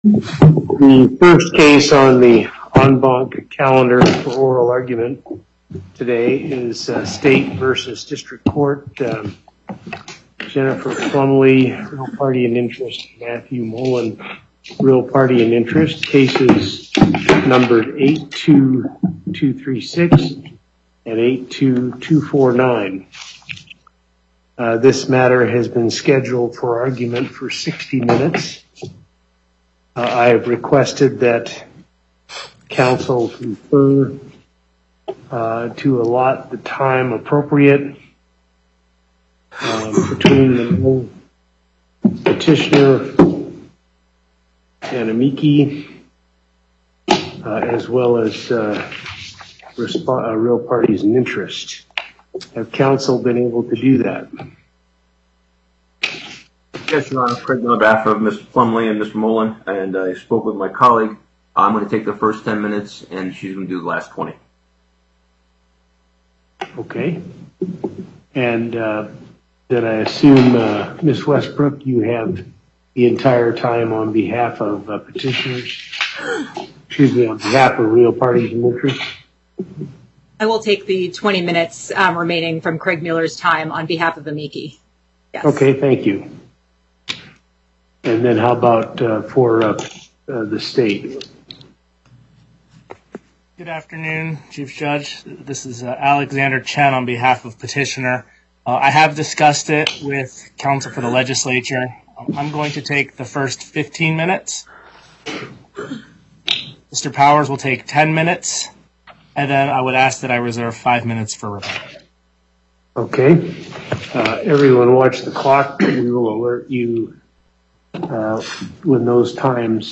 Location: Las Vegas Before the En Banc Court, Chief Justice Hardesty Presiding